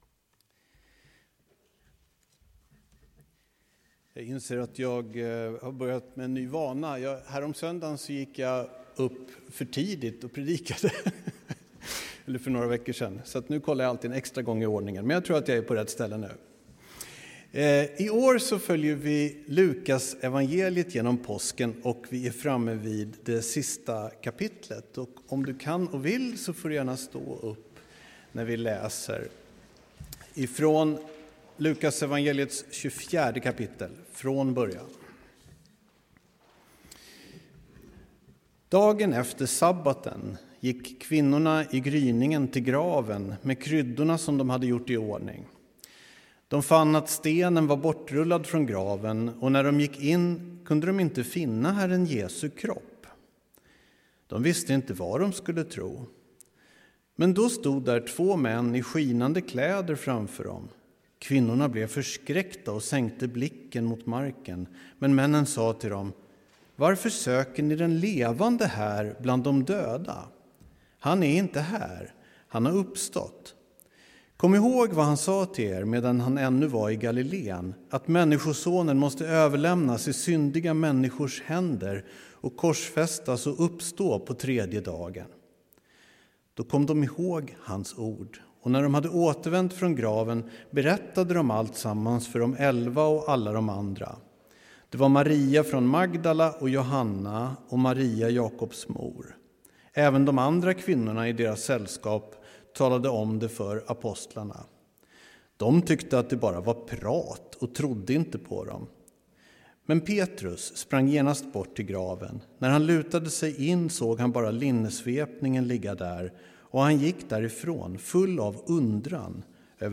Här hittar du inspelningar från gudstjänster och andra tillställningar i Abrahamsbergskyrkan i Bromma i Stockholm. Av upphovsrättsliga skäl rör det sig huvudsakligen om predikningar.